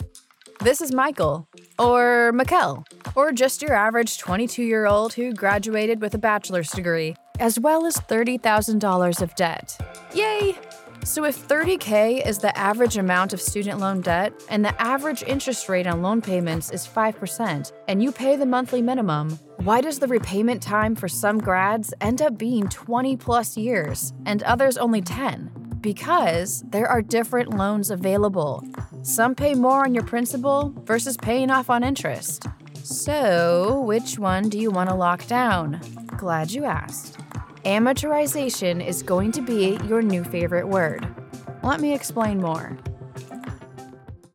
Natürlich, Urban, Warm
Unternehmensvideo